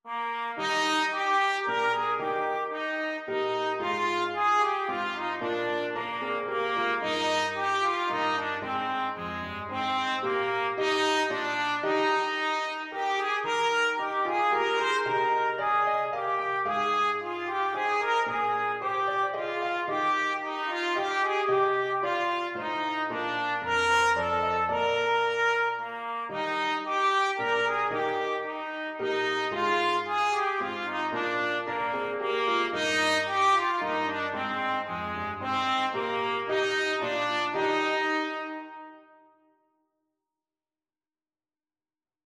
Trumpet
Eb major (Sounding Pitch) F major (Trumpet in Bb) (View more Eb major Music for Trumpet )
3/4 (View more 3/4 Music)
Moderately Fast ( = c. 112)
Classical (View more Classical Trumpet Music)